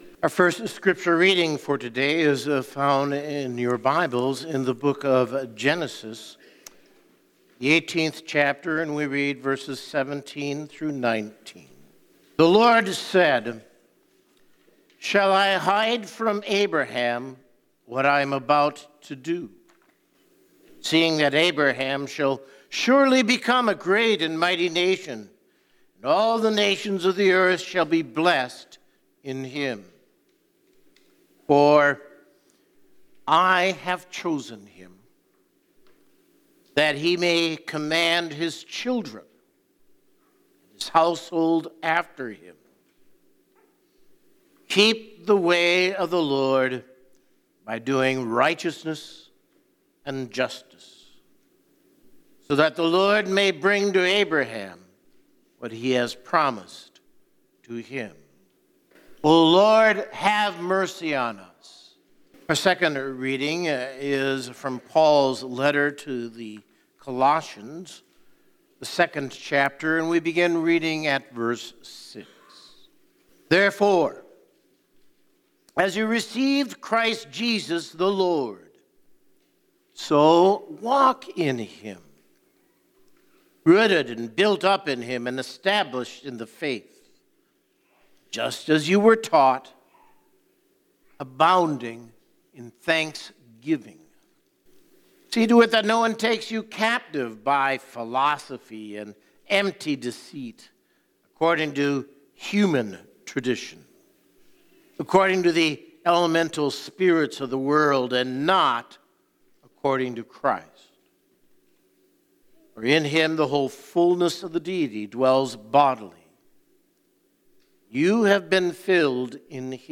Word & Sermon Weekly – Seventh Sunday After Pentecost – 07/24/2022